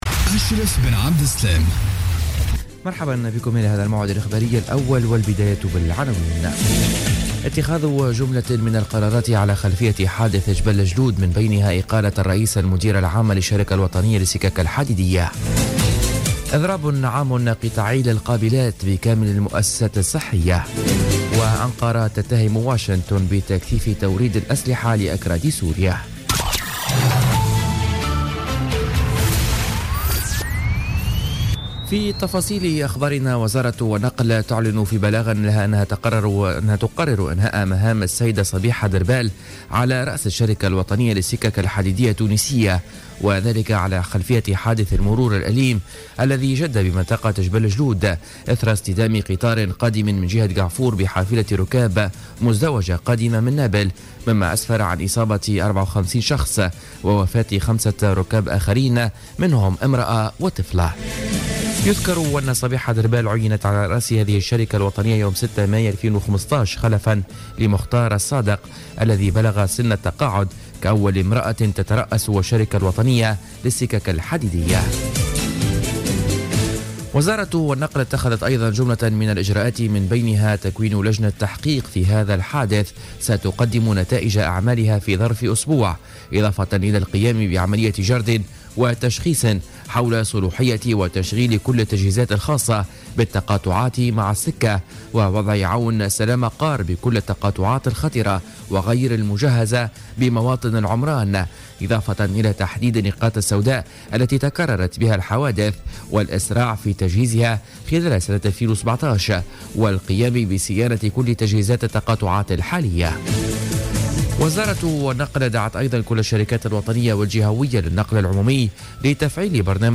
نشرة أخبار السابعة صباحا ليوم الخميس 29 ديسمبر 2016